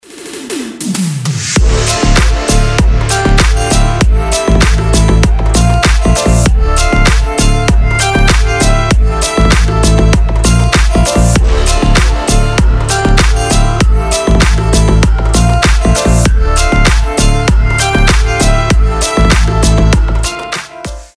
• Качество: 320, Stereo
гитара
deep house
мелодичные
без слов
Восточные ноты в стильном треке